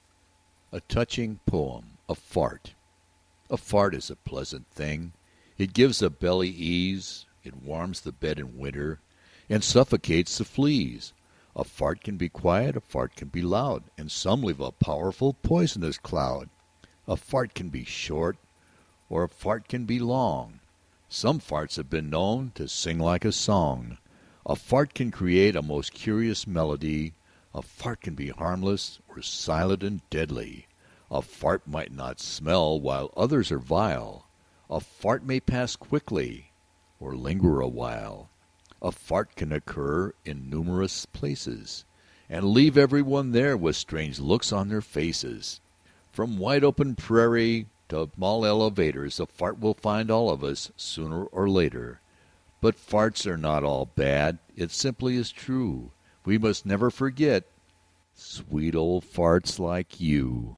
标签： 放屁 放屁 语音 画外音
声道立体声